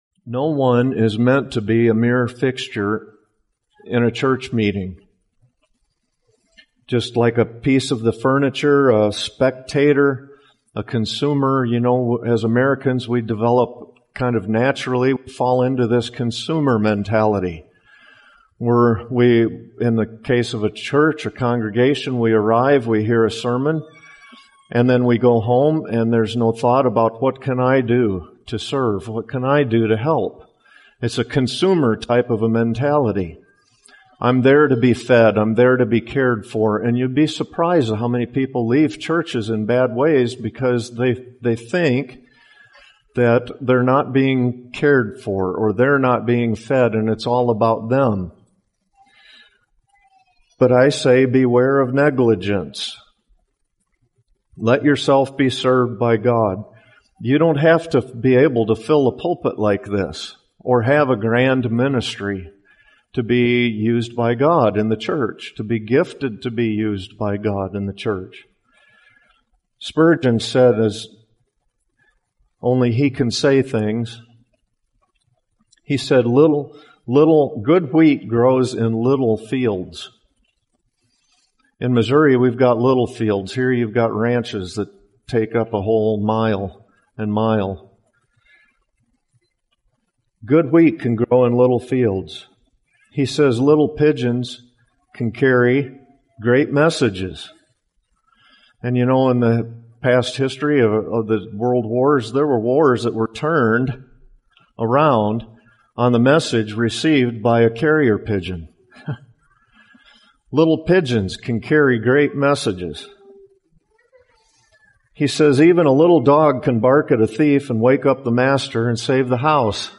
Excerpt | 6:01 | Our world is full of consumers and people who only care about what they can get and not how they can serve.